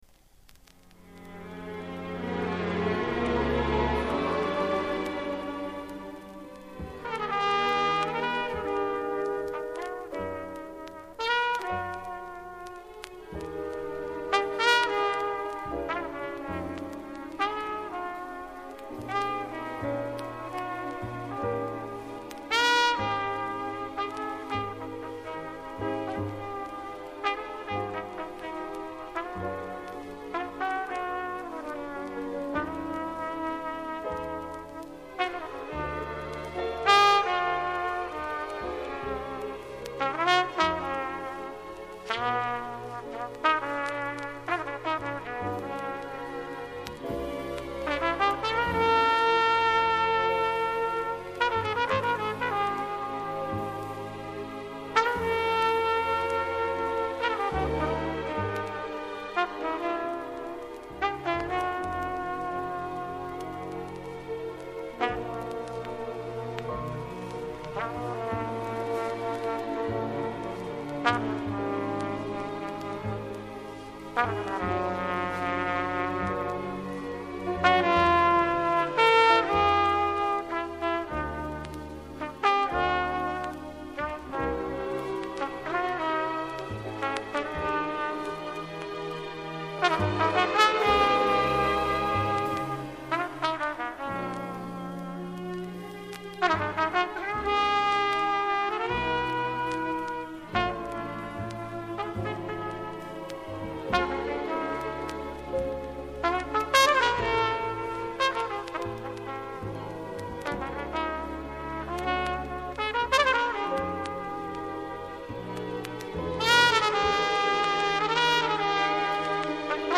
Еше один джазовый музыкант-трубач из Чехословакии